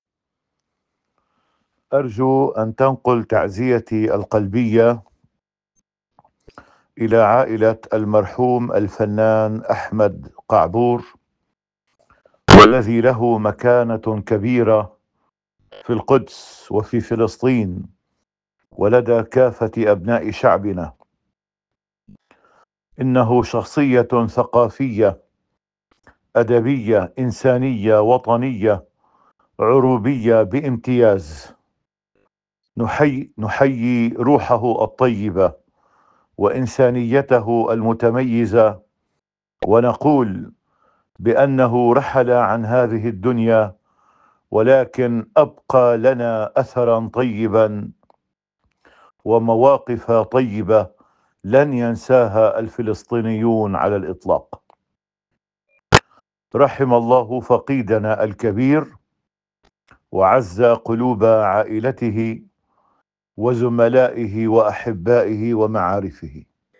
خاص، صوت المطران عطالله حنا معزياً برحيل احمد قعبور